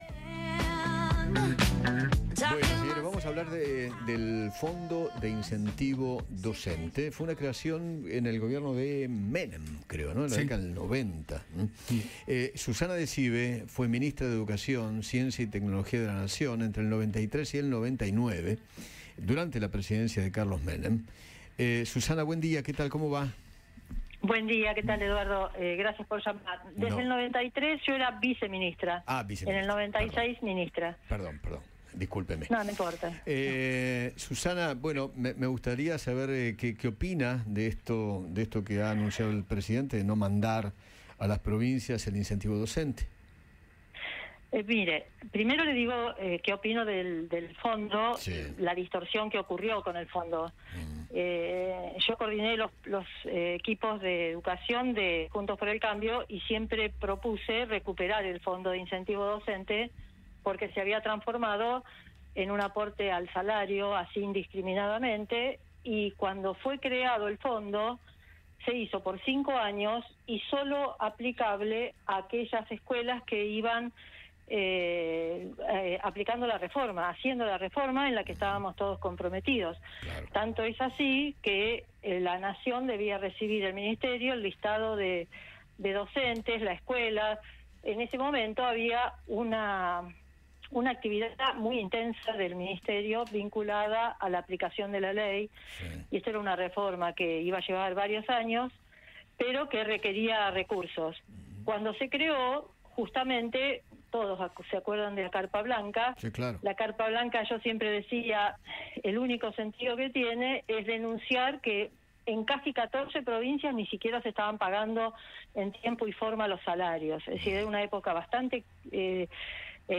Susana Decibe, ex ministra de Educación, Ciencia y Tecnología (1996 – 1999), dialogó con Eduardo Feinmann sobre la importancia del fondo de incentivo docente.